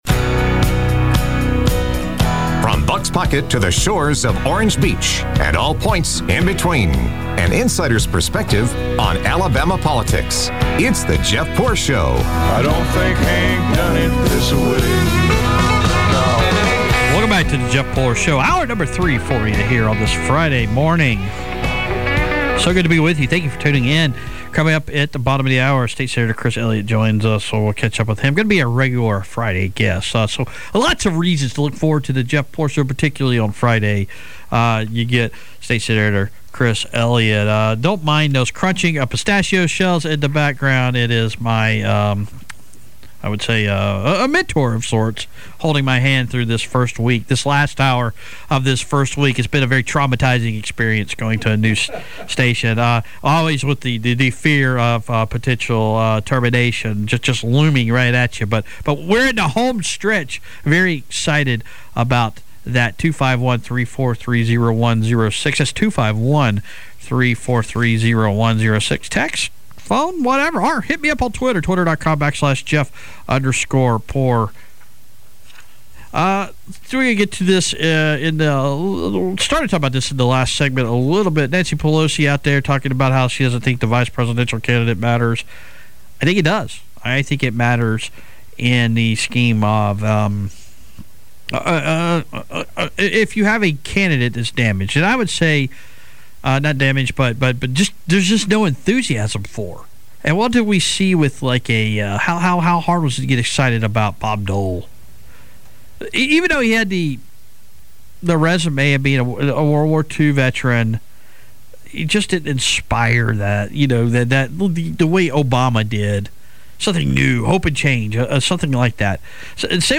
interviews State Sen Chris Elliott